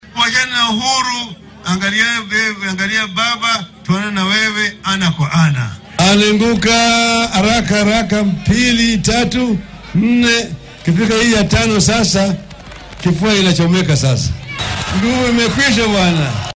Hasa ahaate William Ruto oo ku sugnaa ismaamulka Marsabit ayaa dhankiisa ka soo horjeestay in la isticmaalo diiwaanka caadiga ah waxaa uuna guddiga IEBC ku waafaqay adeegsiga qaabka casriga ah.